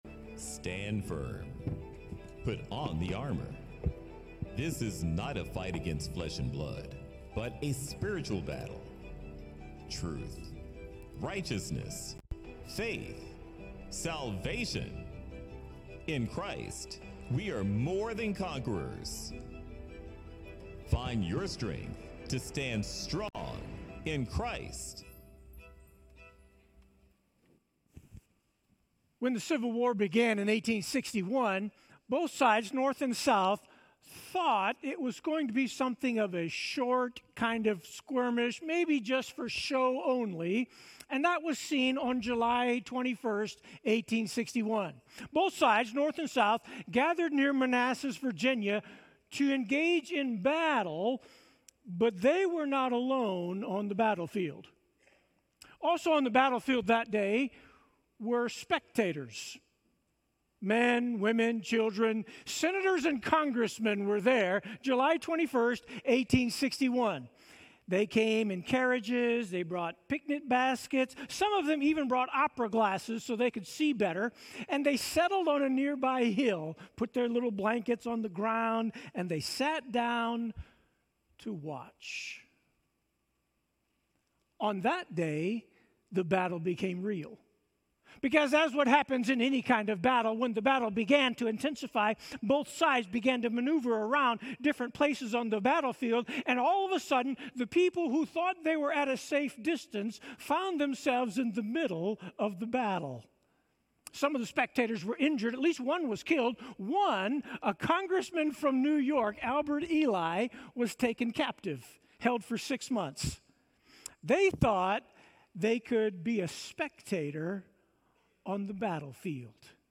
Sermons - Sunnyvale FBC